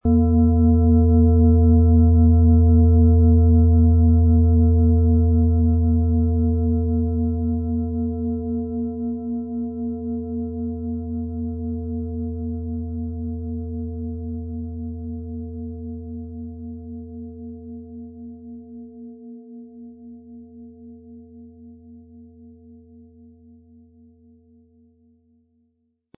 Hopi Herzton
PlanetentöneHopi Herzton & Mond & Venus (Höchster Ton)
MaterialBronze